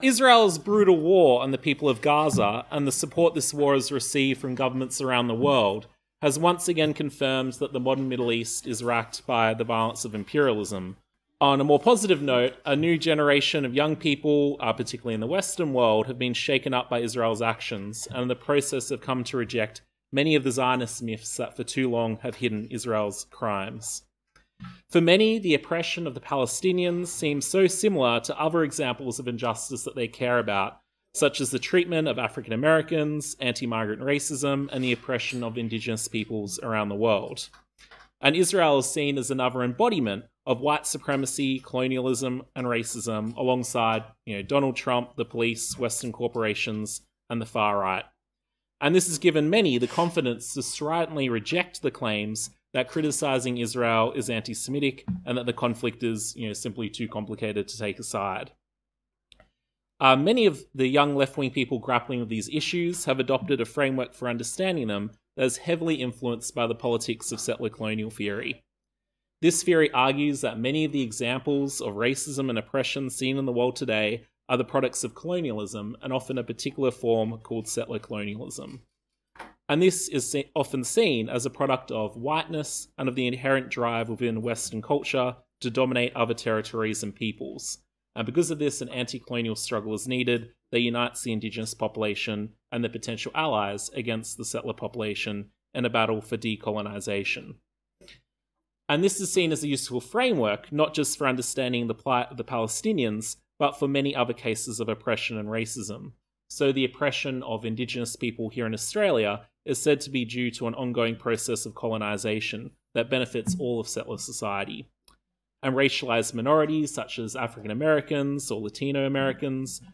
Play talk